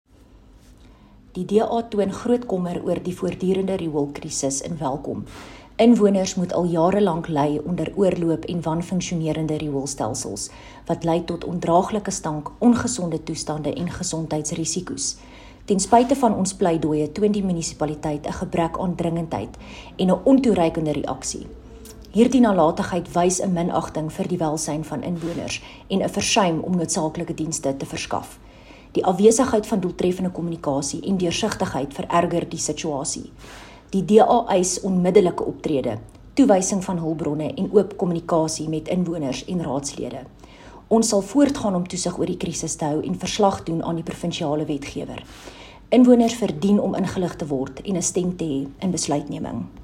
Afrikaans soundbites by Cllr René Steyn.